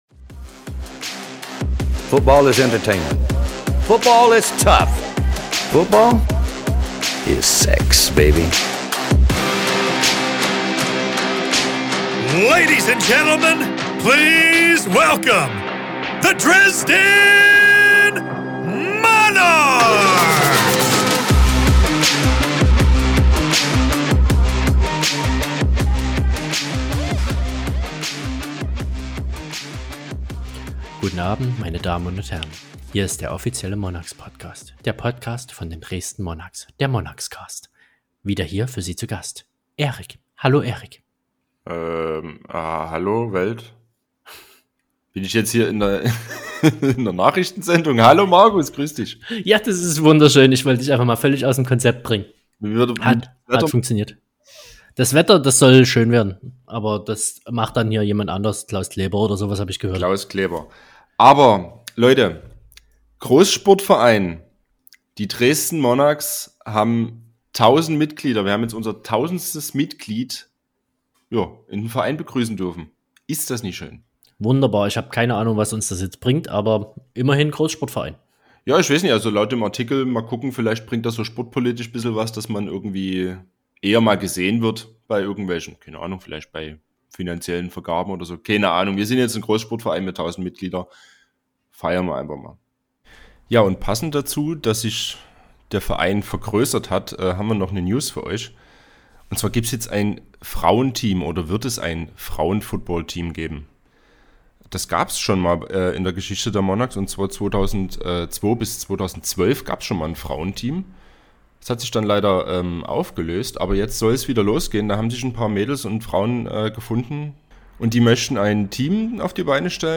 Neben der obligatorischen Analyse des letzten Spiels und der Vorschau auf das Kommende, haben wir noch einige News, Neuverpflichtungen und ein neues Gewinnspiel für euch. Zwei der ''Neuen'' haben wir uns direkt zum Interview geschnappt.